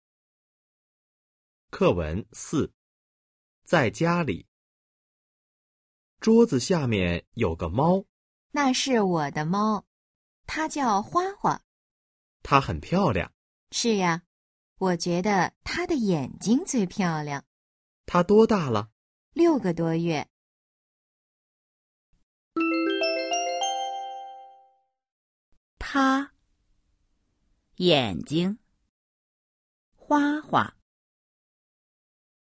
Bài hội thoại 4: 在家里 – Ở nhà  💿 01-04